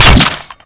weap_hvy_gndrop_2.wav